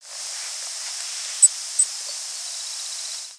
Baird's Sparrow diurnal flight calls
Diurnal calling sequences: